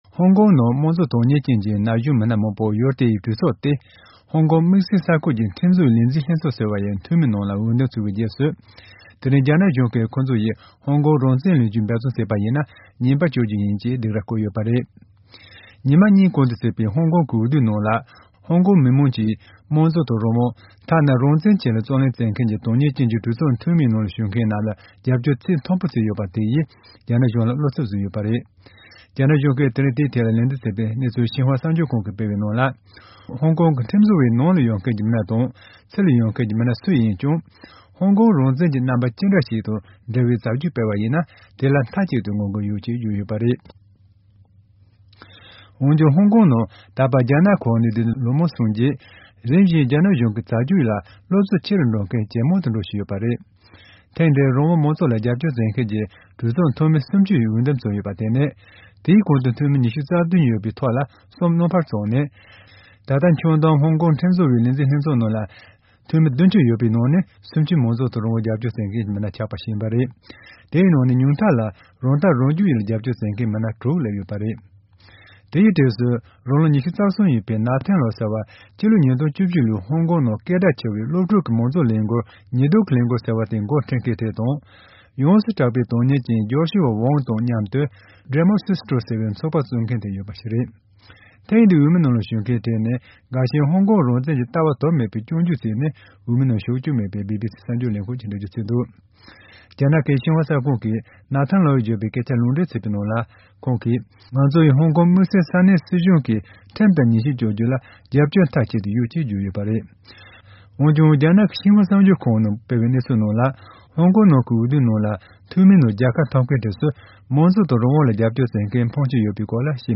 སྙན་སྒྲོན་གནང་གི་རེད།